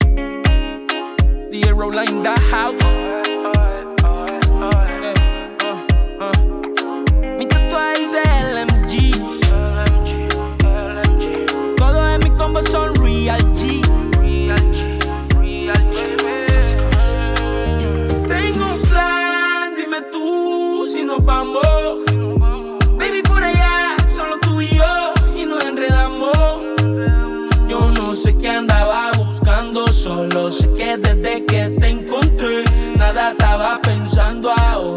Жанр: Латиноамериканская музыка
# Latino